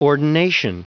Prononciation du mot ordination en anglais (fichier audio)
Prononciation du mot : ordination